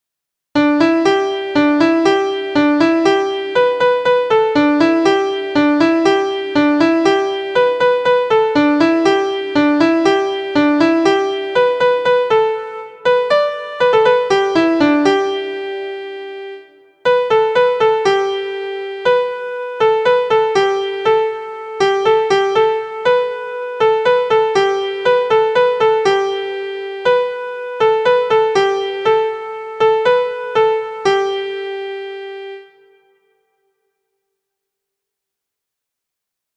Challenging rhythms and intervals for Christmas or Easter.
• Origin: African American Spiritual
• Key: G Major
• Time: 2/4
• Form: Verse: AAAB – Refrain: ABAC